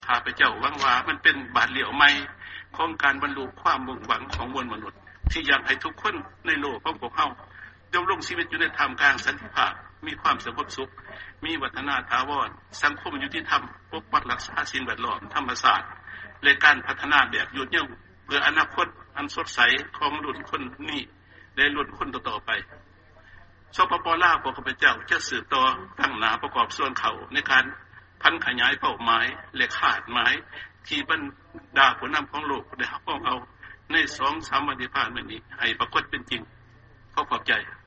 ຟັງຖະແຫລງການ ປະທານປະເທດ ຈູມມະລີ ໄຊຍະສອນ 11